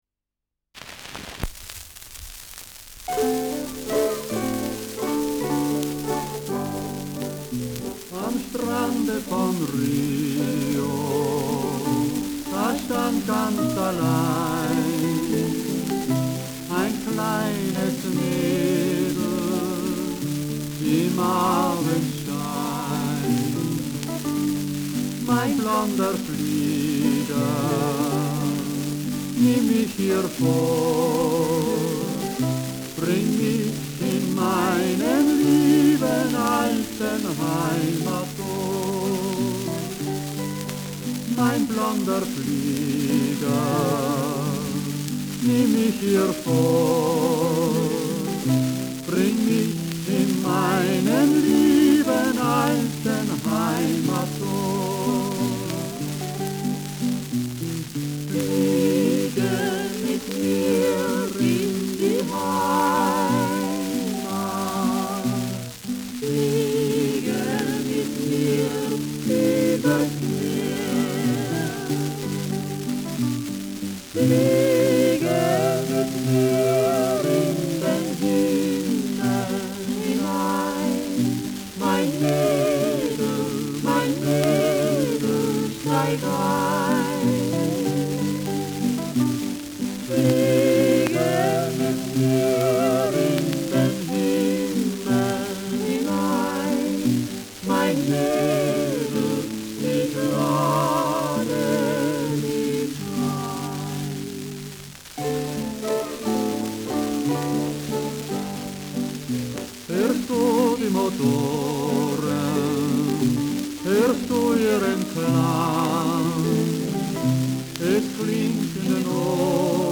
Schellackplatte
Tonrille: Kratzer 2 Uhr Stärker
präsentes Rauschen
Folkloristisches Ensemble* FVS-00015